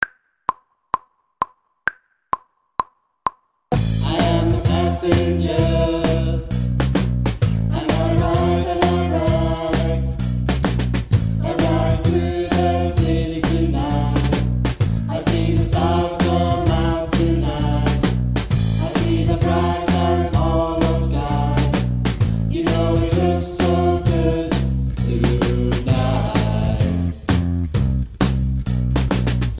Passenger vocals section 2